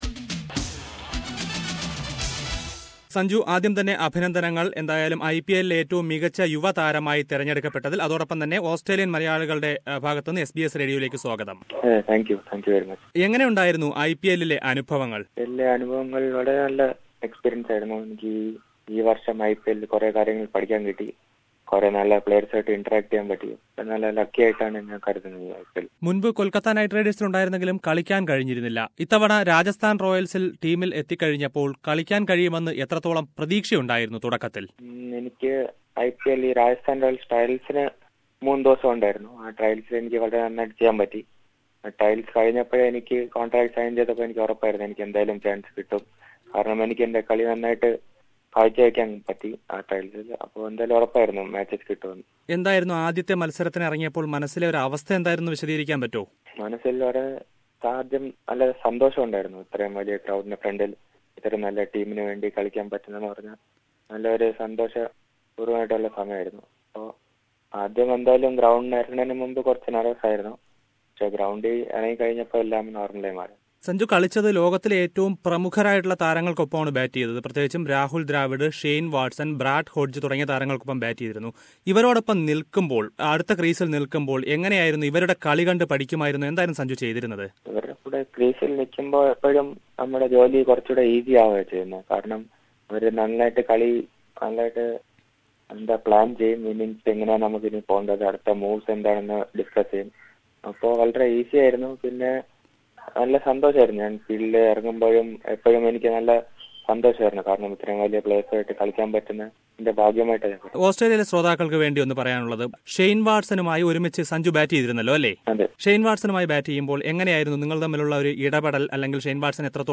While Rajasthan Royal player Sreesanth's arrest gave embarrassment, 18 year old Sanju Samson from the same team held Keralam's esteem high. The best young player of this season's IPL talks to SBS Malayalm in an exclusive interview